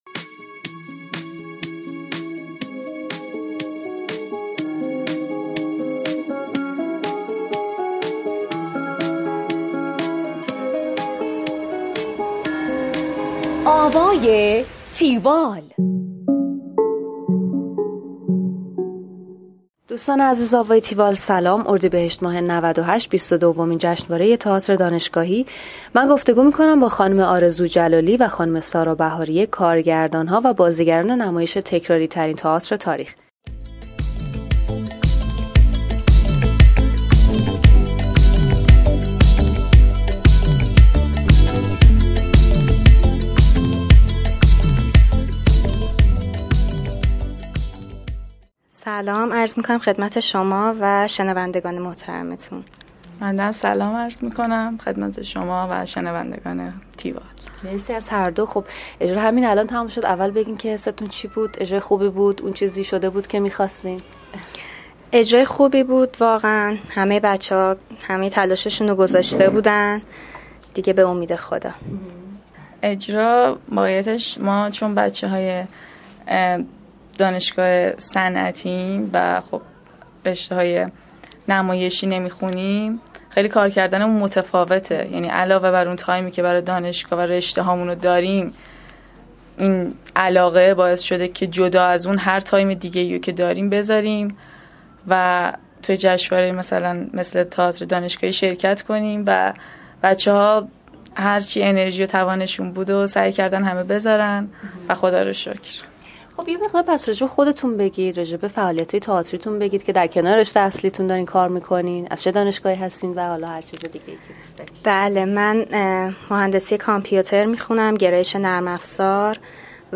tiwall-interview-theatertekrari.mp3